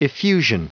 Prononciation du mot effusion en anglais (fichier audio)
Prononciation du mot : effusion